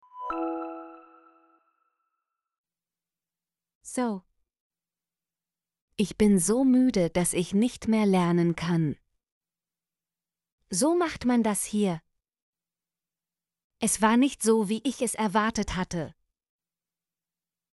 so - Example Sentences & Pronunciation, German Frequency List